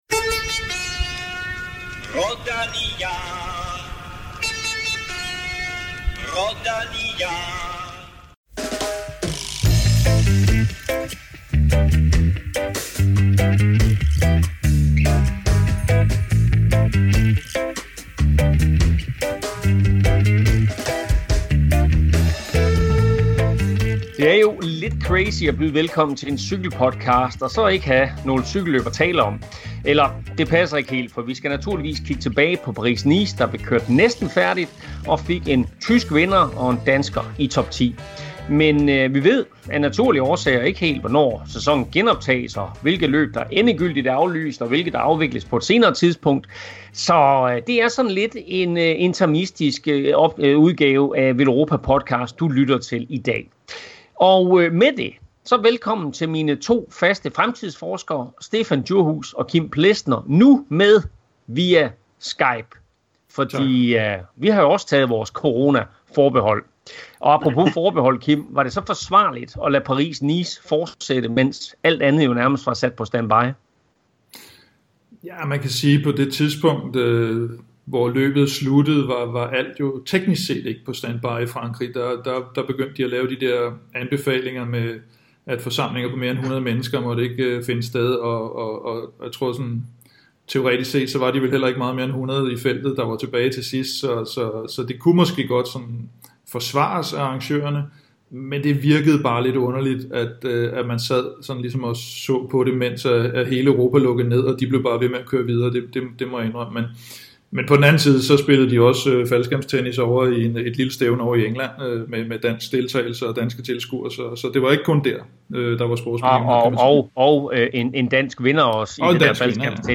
Vi lytter til myndighederne og derfor er episode 95 af Veloropa Podcast optaget via Skype. Lydniveauet er noget dårligere end sædvanligt, men indholdet kan jo heldigvis ikke blive meget ringere, end det i forvejen er.